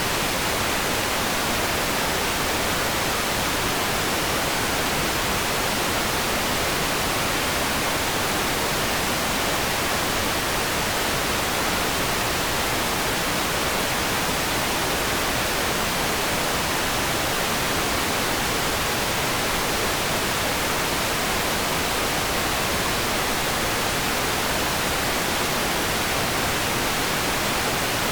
stick-the-quick/audio/ambience/rain_level_5.ogg at 6287e74ba5885a0428b70976a4fd5f79dfe435c0
rain_level_5.ogg